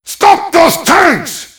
mvm_tank_alerts12.mp3